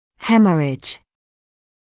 [‘hemərɪʤ][‘хэмэридж]кровотечение; кровоизлияние (US hemorrhage); истекать кровью